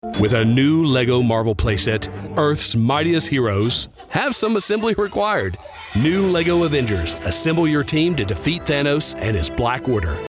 North American General, North American Southern
Commercial
He operates from a professional home studio equipped with an MKH416 microphone and Logic Pro, ensuring broadcast-quality audio with fast turnaround times.